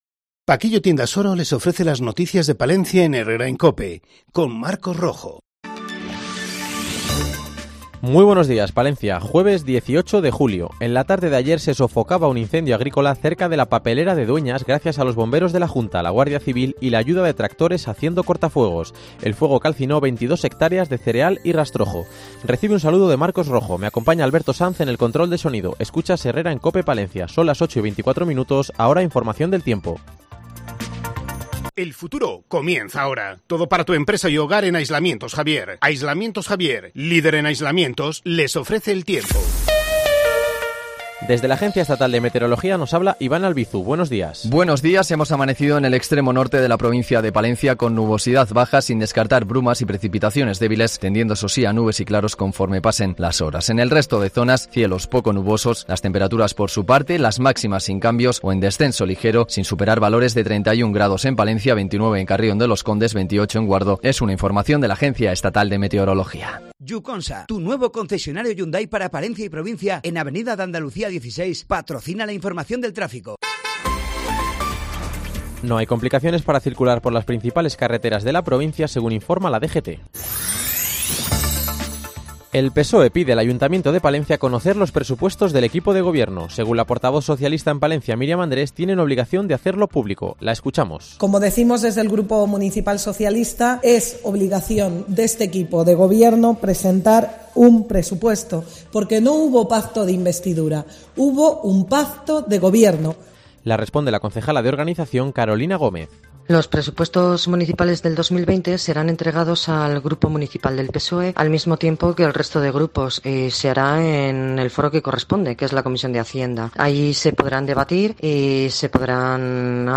INFORMATIVO 08,24 HORAS 18-07-19